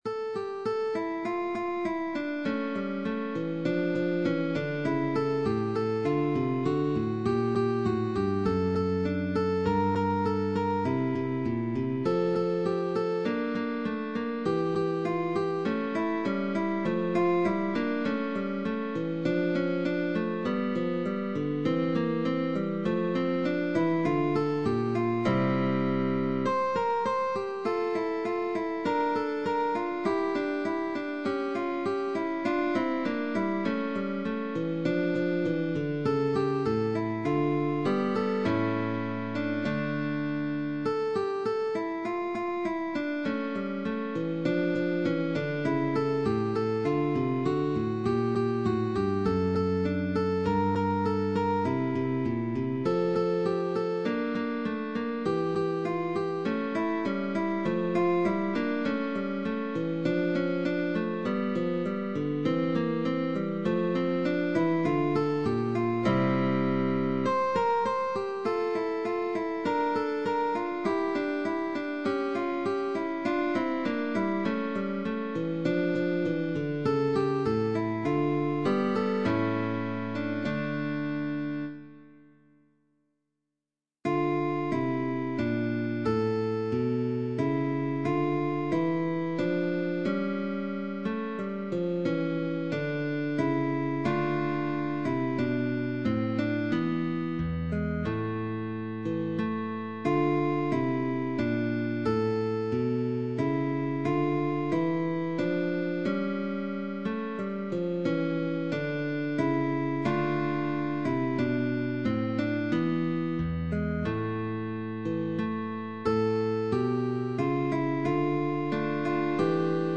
Prelude, Sarabande, Gavotte, Gigue.